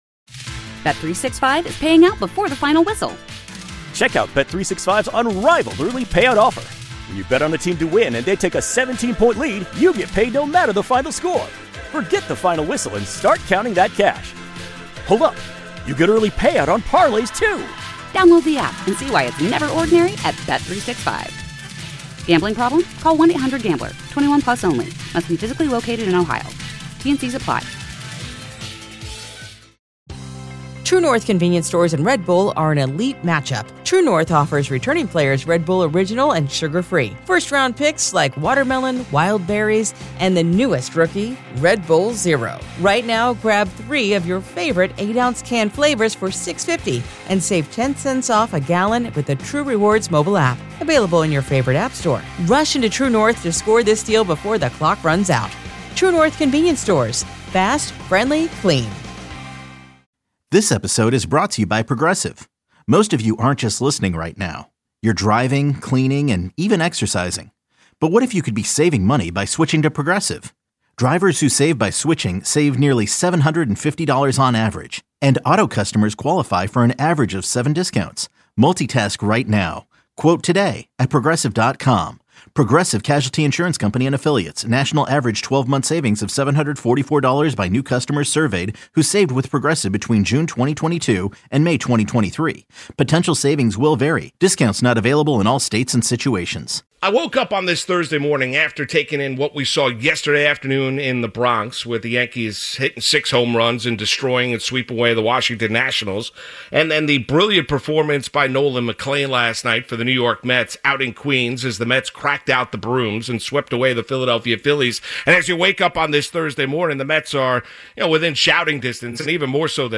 In his fiery monologue